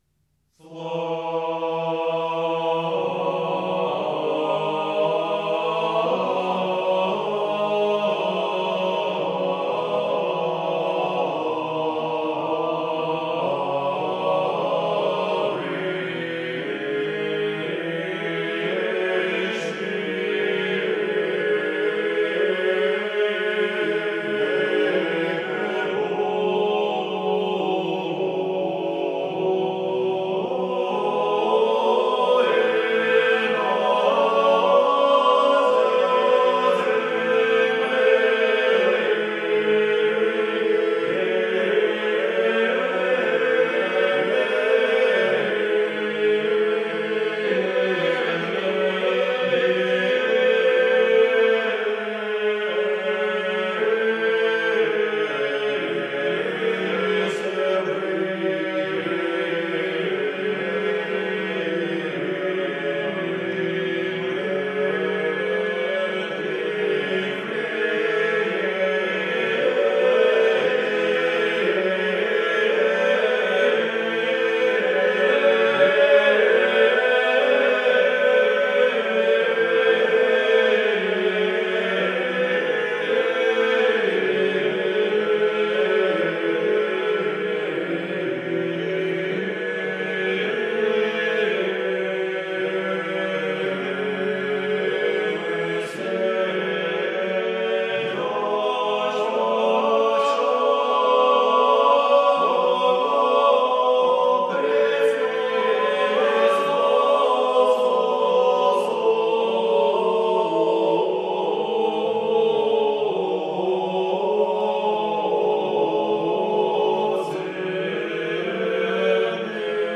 Рождественские песнопения и колядки